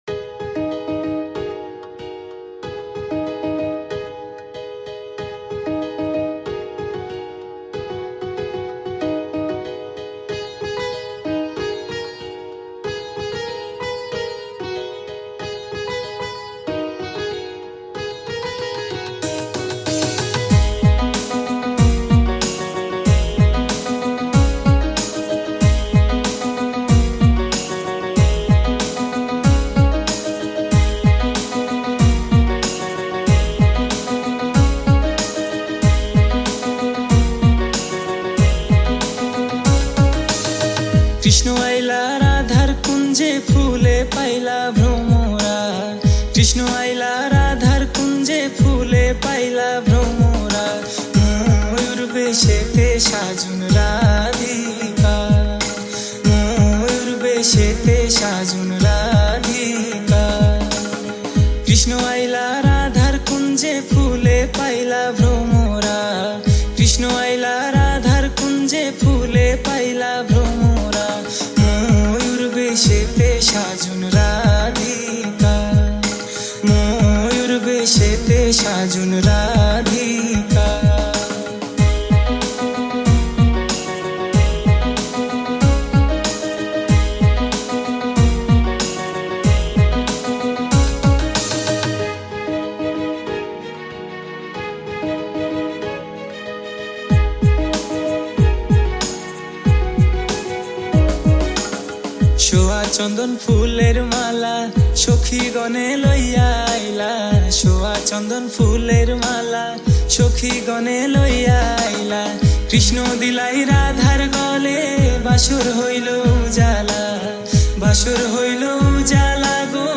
Category: Bangla Mp3 Songs